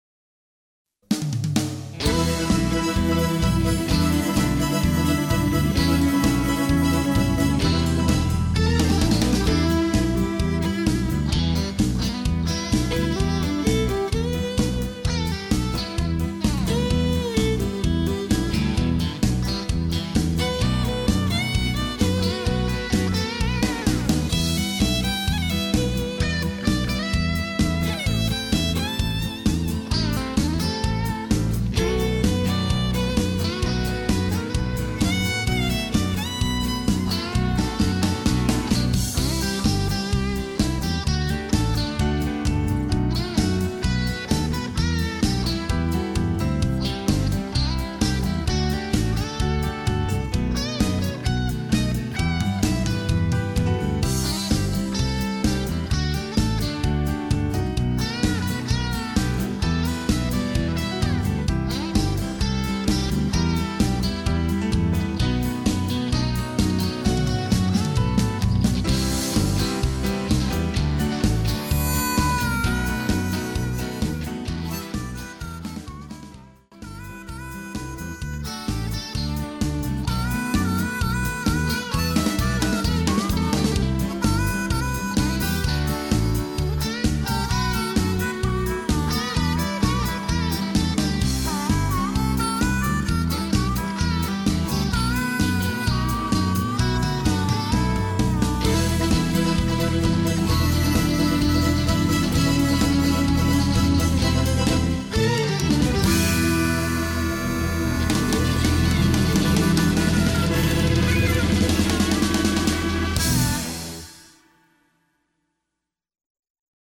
Instrumental TRacks